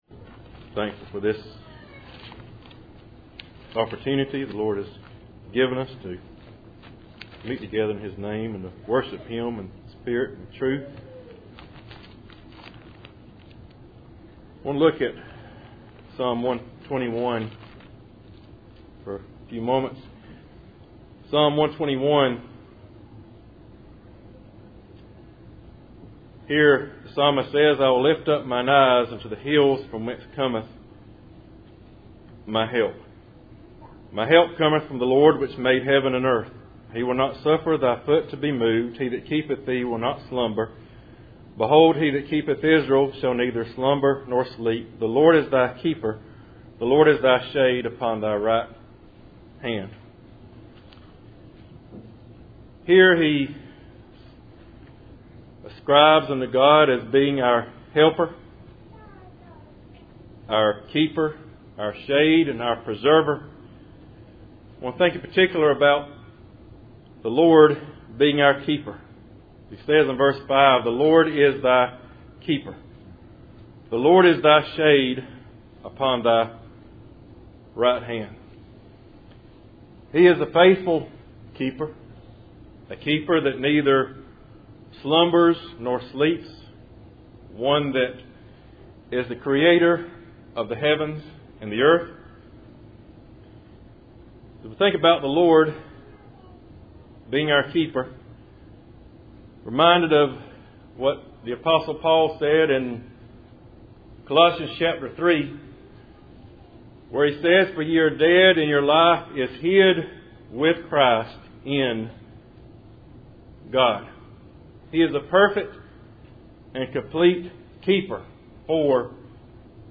Psalm 121:0 Service Type: Cool Springs PBC Sunday Evening %todo_render% « Being Thankful in One Body God’s Ability and Will